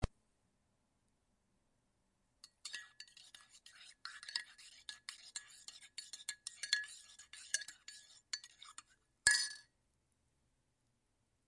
搅拌咖啡
Tag: 搅拌 咖啡